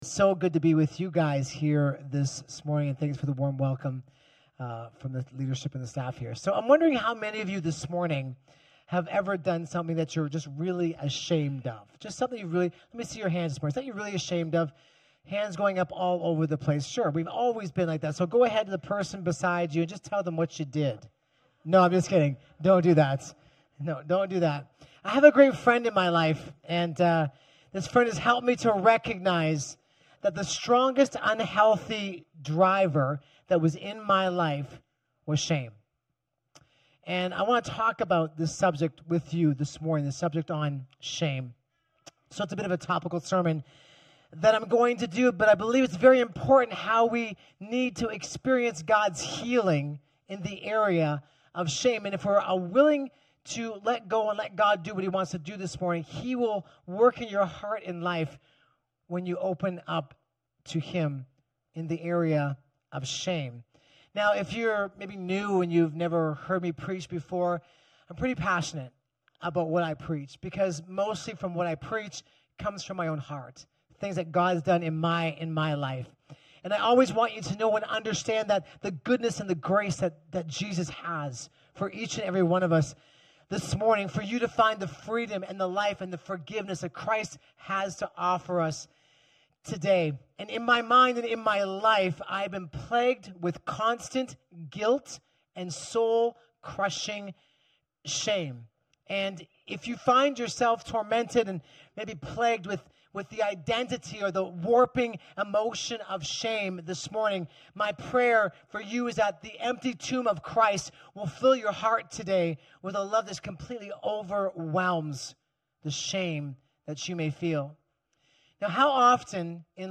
From Series: "Guest Preachers"